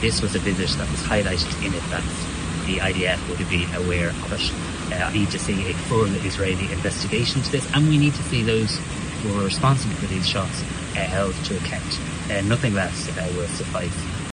Tanaiste Simon Harris says the incident is totally unacceptable: